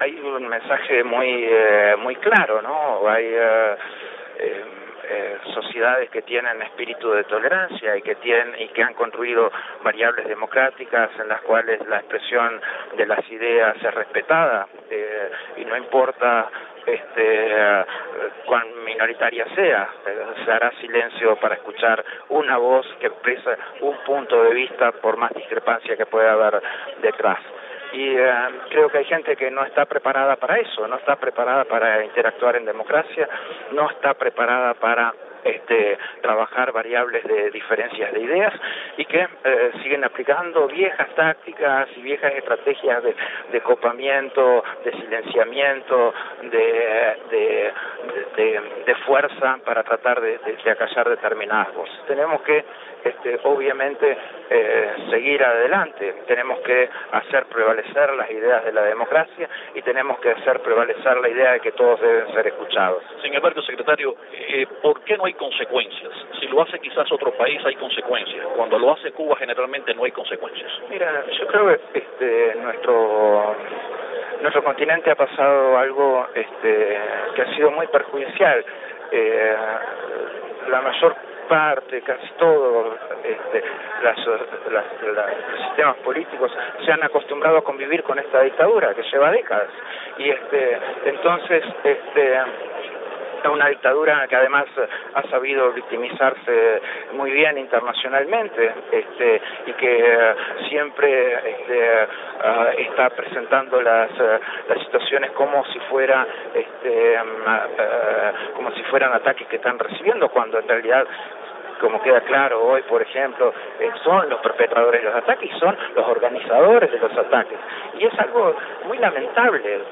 Declaraciones desde Lima del secretario general de la OEA, Luis Almagro